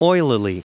Prononciation du mot oilily en anglais (fichier audio)
Prononciation du mot : oilily